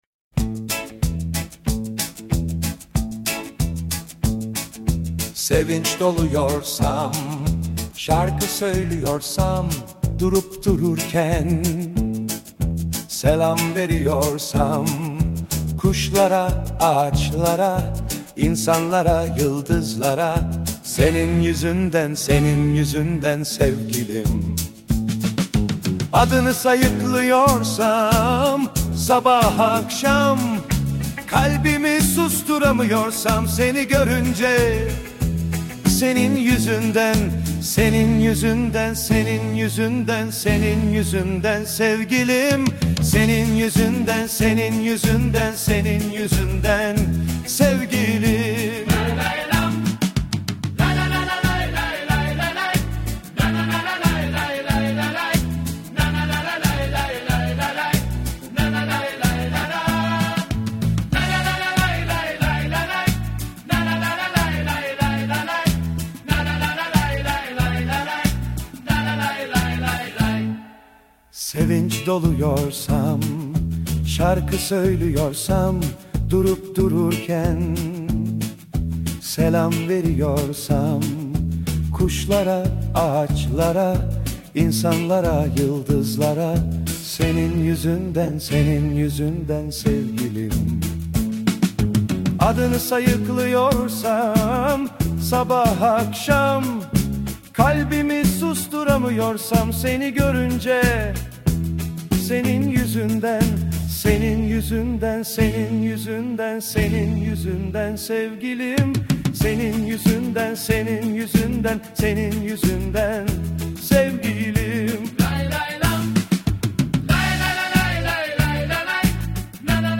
Tür : Pop, Rock